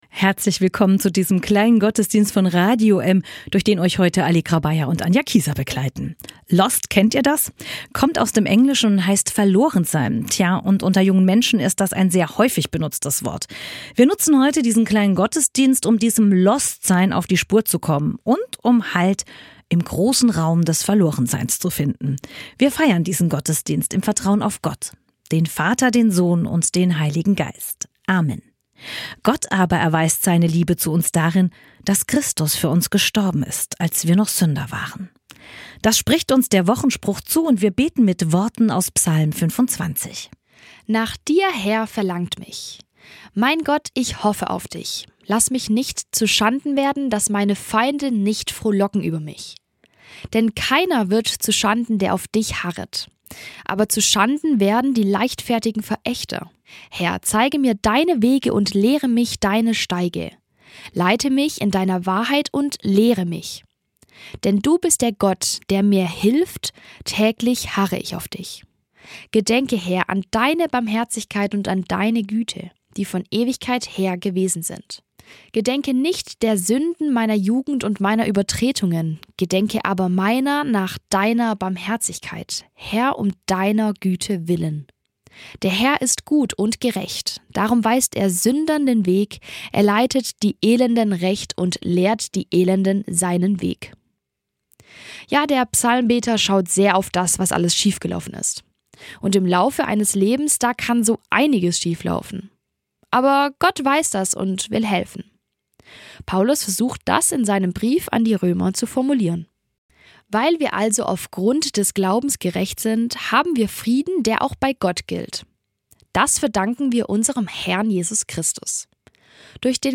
Wochenspruch – Röm 5, 8 Psalmgebet – Ps 25, 1-9 Predigttext – Röm 5, 1-5 Ein Dank an die Deutsche Bibelgesellschaft für die Nutzung der Textrechte.